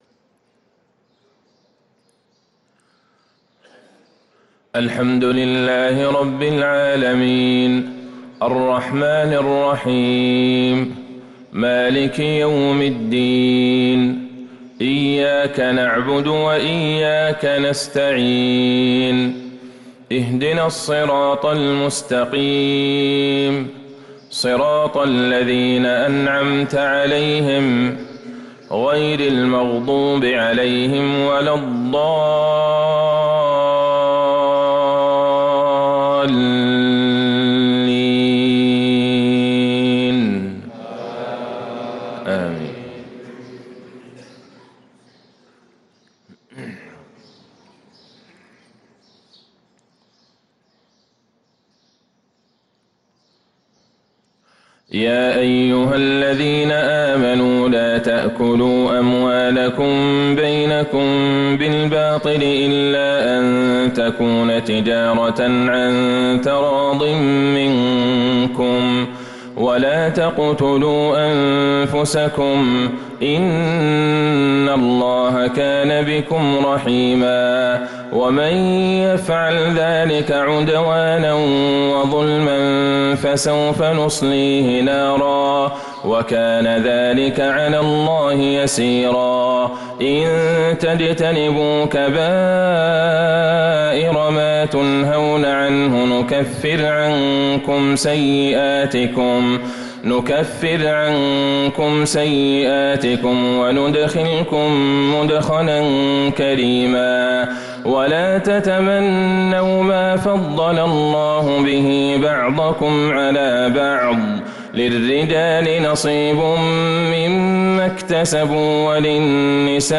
صلاة الفجر للقارئ عبدالله البعيجان 22 جمادي الآخر 1445 هـ
تِلَاوَات الْحَرَمَيْن .